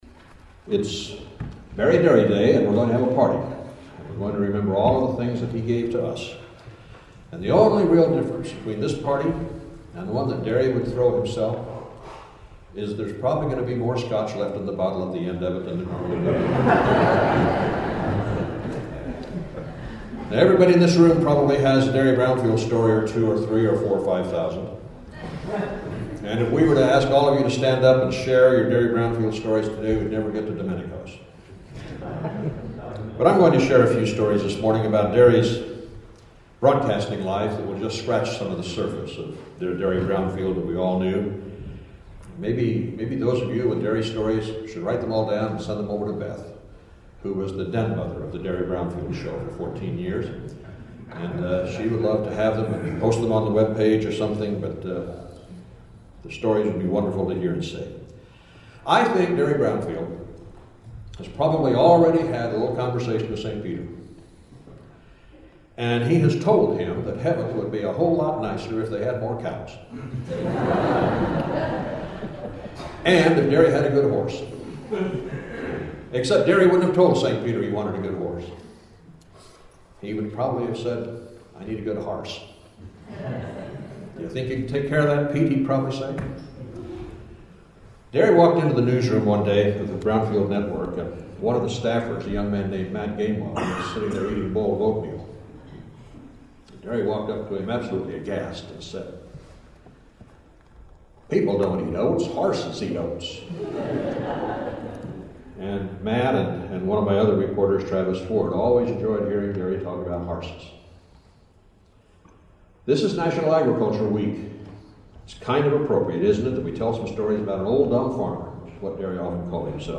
Eulogy